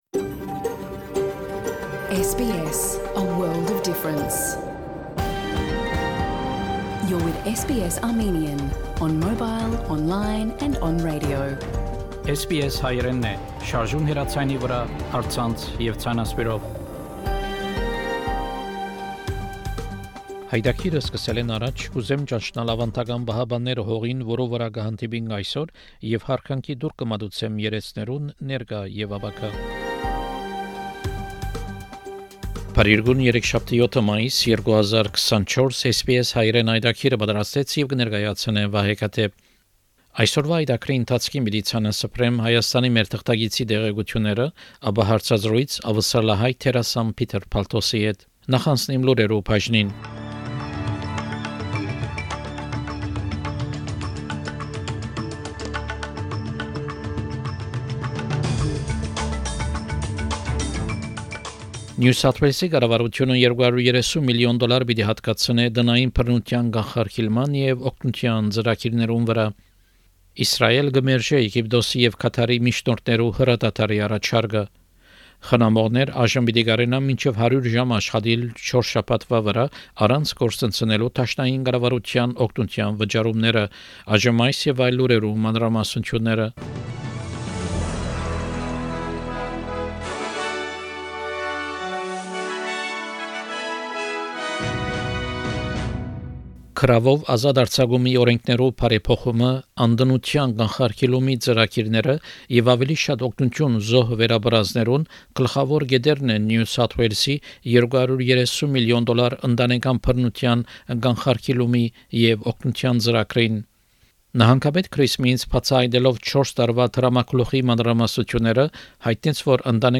SBS Armenian news bulletin – 7 May 2024
SBS Armenian news bulletin from 7 May program.